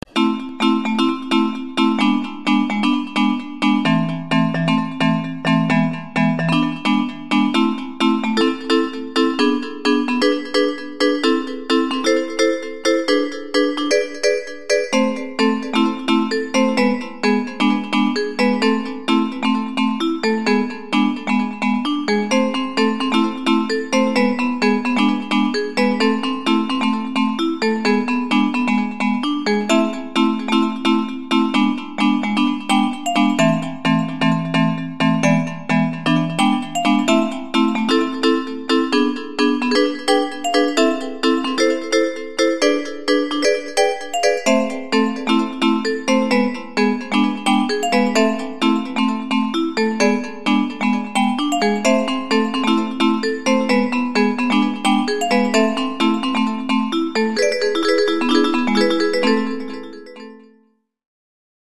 but they just sound like standard phone ringtones to me